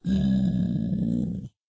minecraft / sounds / mob / zombiepig / zpig2.ogg